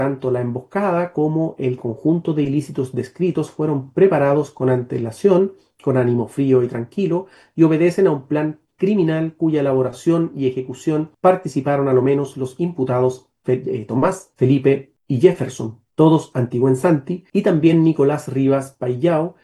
El juez Marcos Pincheira, presidente de la sala, en la lectura de la causa, se refirió a la organización del homicidio, afirmando que fue planificado y estudiado.